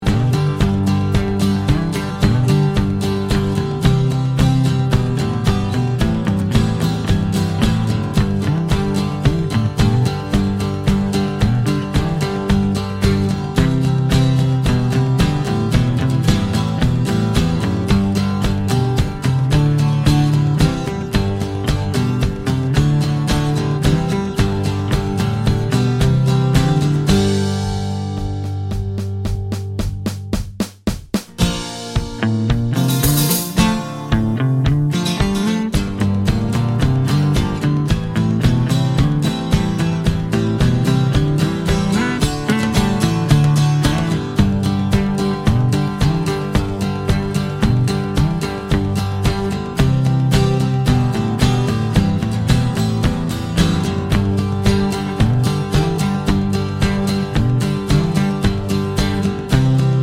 Two Semitones Down Pop (1960s) 2:29 Buy £1.50